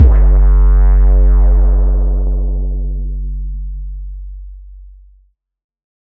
808 Kick 28_DN.wav